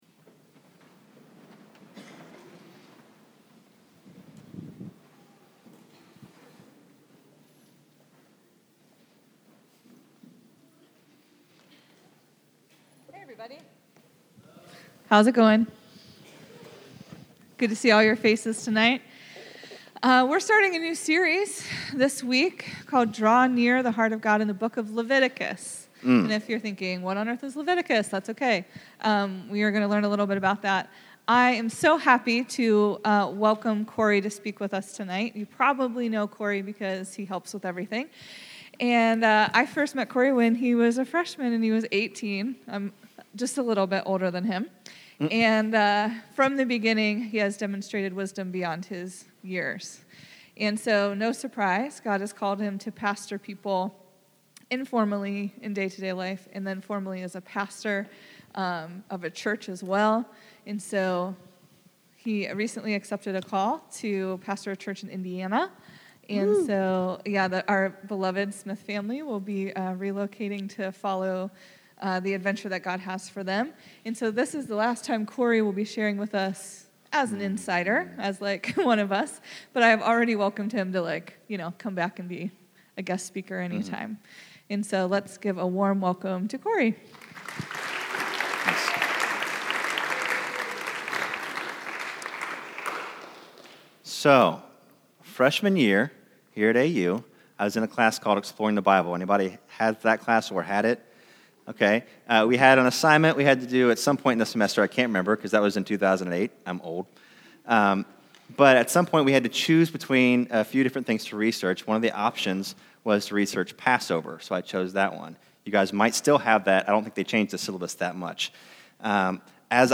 Sermons - The Well